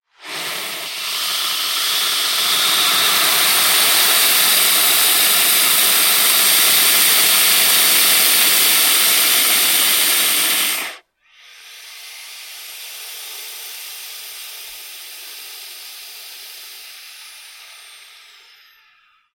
На этой странице собраны реалистичные аудиозаписи: от угрожающего шипения до плавного скольжения по поверхности.
Звук шипения питона перед атакой в целях самозащиты